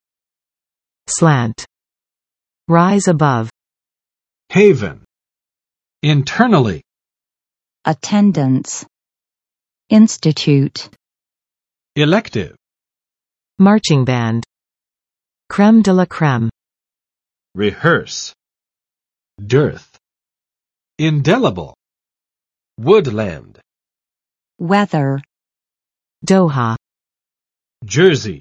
[slænt] n.【美】【口】观点，看法，倾向；偏见